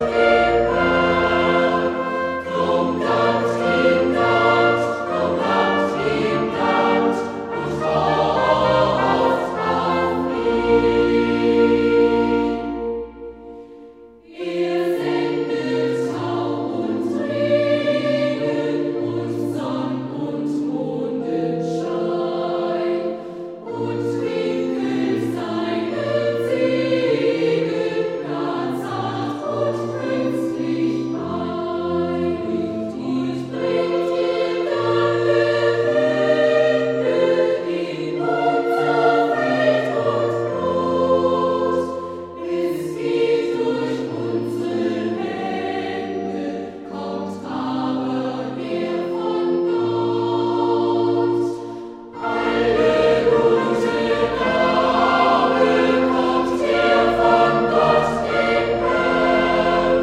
Chormusik/Evangeliumslieder